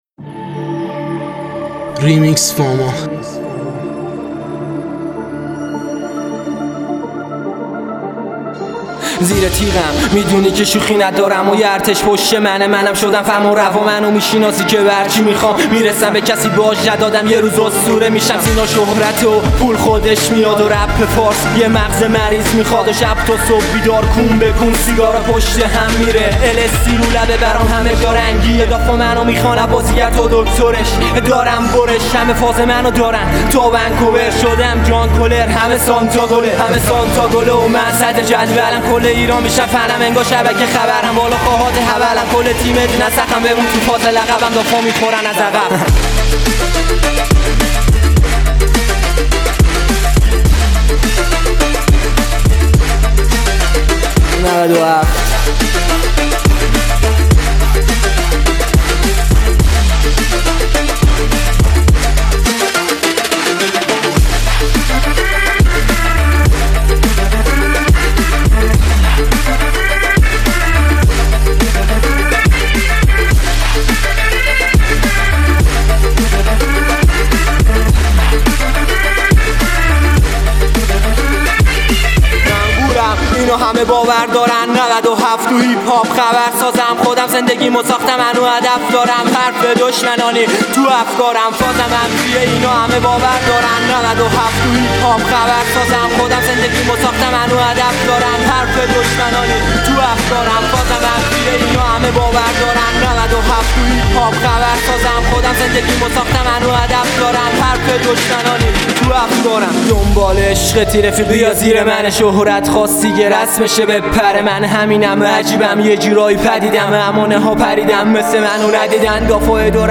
آهنگ گنگ و خفن